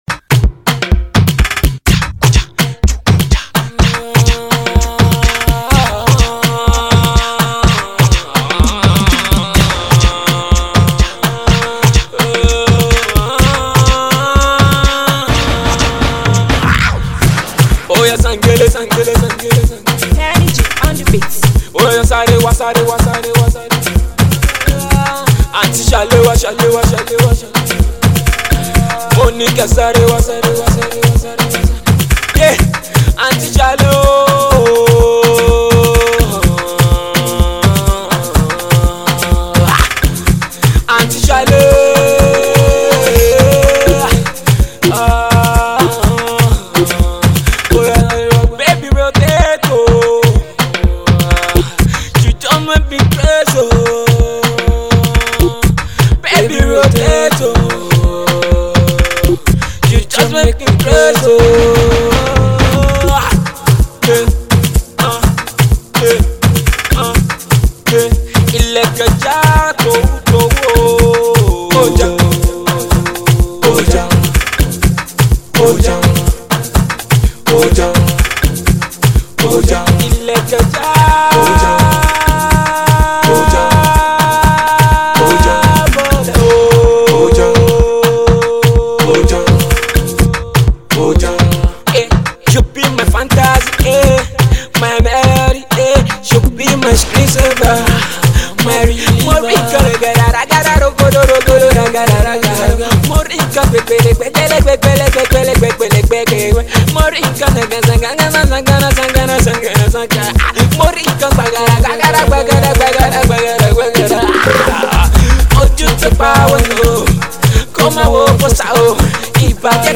Alternative Pop
young alternative Yoruba rapper